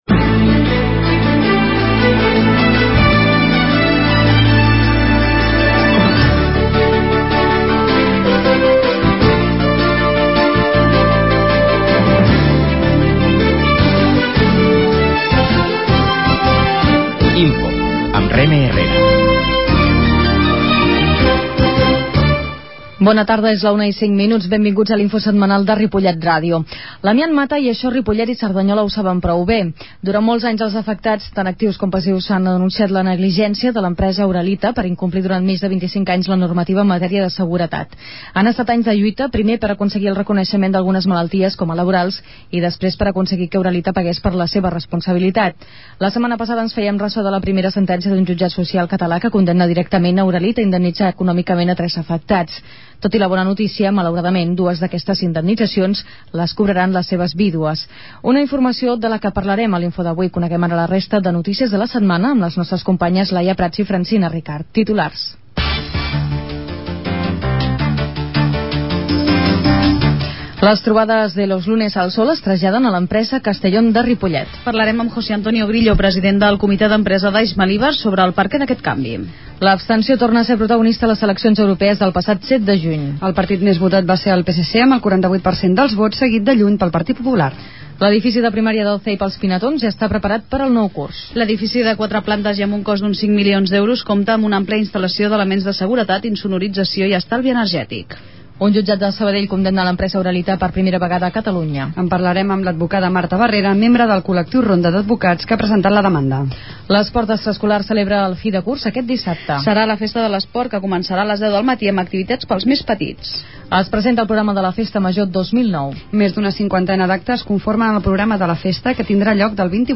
Comunicació INFO de la setmana: 12 de juny de 2009 -Comunicació- 11/06/2009 Escolteu en directe per la r�dio o la xarxa el resum de not�cies de Ripollet R�dio (91.3 FM), que s'emet en directe a les 13 hores.
La qualitat de so ha estat redu�da per tal d'agilitzar la seva desc�rrega.